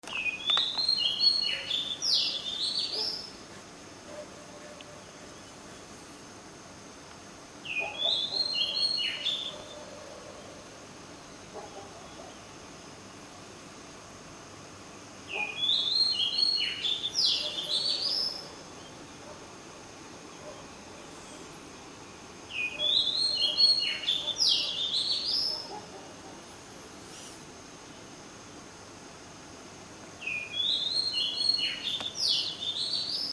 Attached is the sound of a Mapie Robin I recorded yesterday morning (5:00am 15/5) for comparison.
mapierobin01.mp3